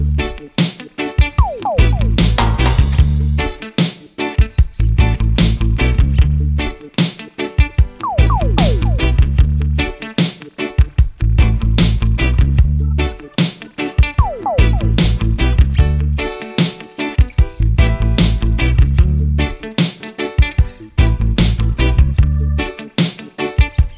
no Backing Vocals Reggae 3:31 Buy £1.50